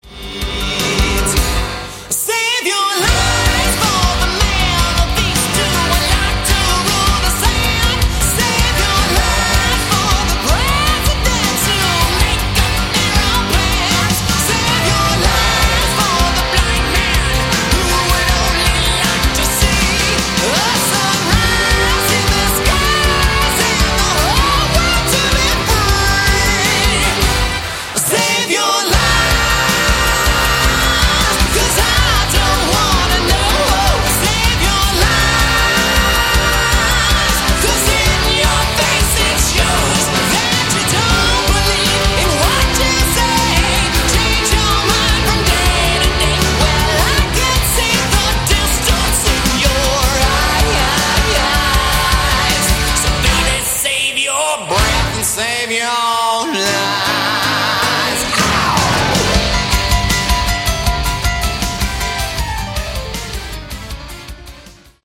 Category: Hard Rock
lead vocals
keyboards, vocals
bass, vocals
drums